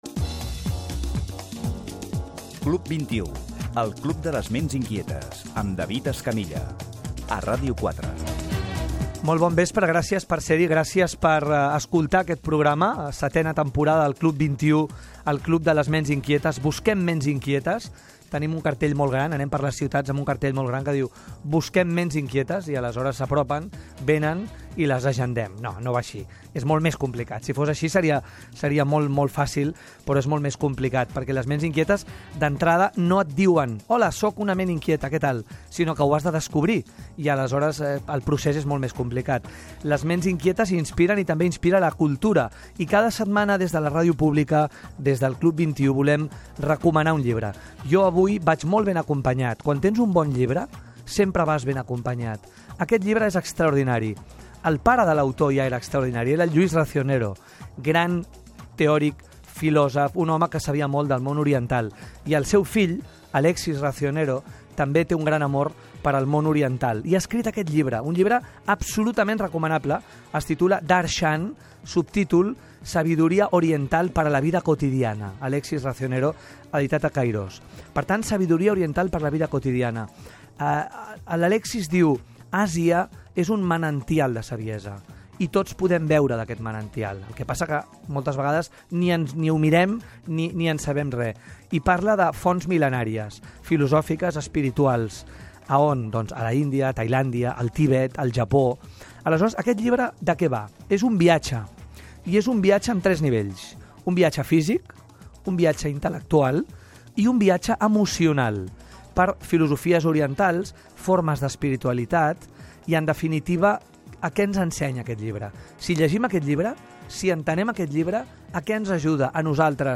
Careta del programa, presentació